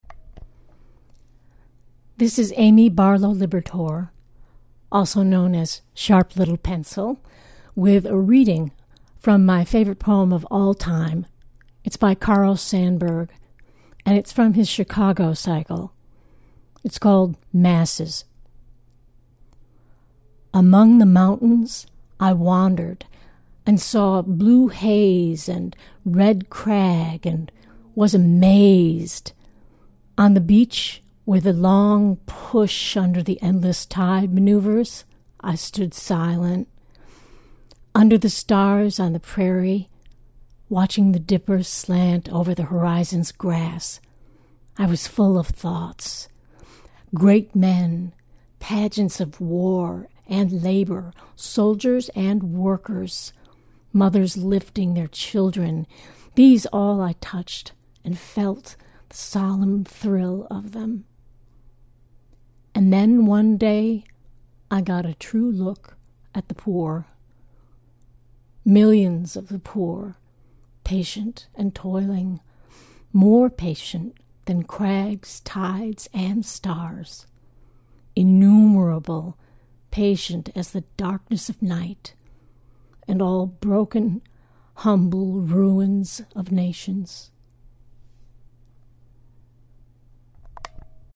sandburg-chicago-masses.mp3